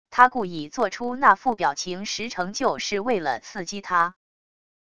他故意做出那副表情十成就是为了刺激他wav音频生成系统WAV Audio Player